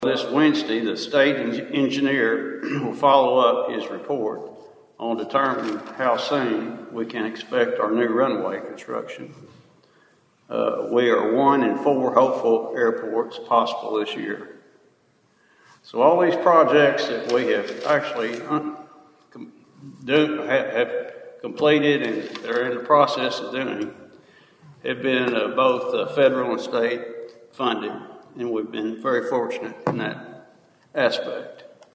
The Princeton City Council’s meeting Monday night was brief but packed with positive updates, as department heads shared news of new hires, ongoing projects, and community events.